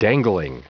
Prononciation du mot dangling en anglais (fichier audio)
Prononciation du mot : dangling